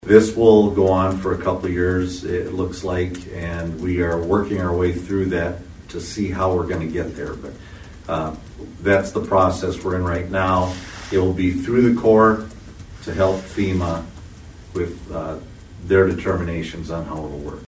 ABERDEEN, S.D.(HubCityRadio)- Toward the end of Monday’s City Council meeting, Aberdeen City Manager Robin Bobzien addressed couple issues dealing with the city of Aberdeen.